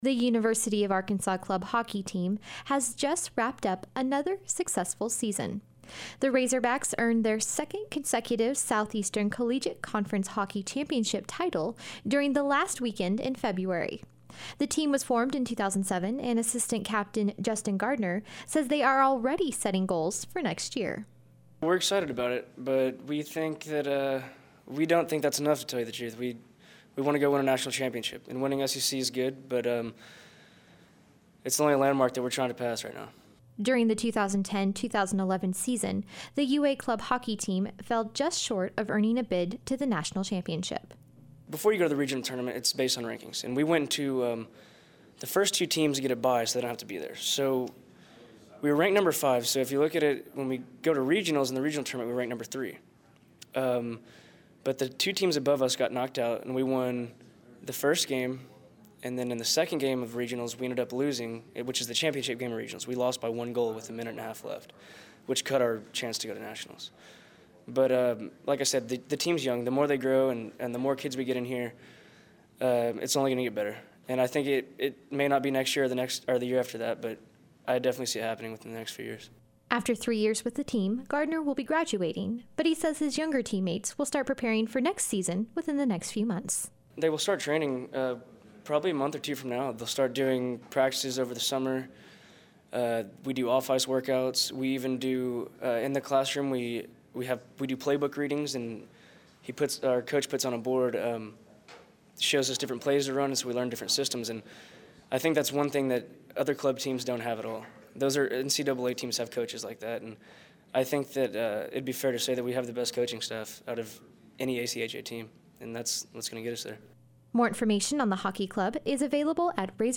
The UA Club Hockey Team hosted a press conference yesterday to recap its successful season and discuss its goals for the future.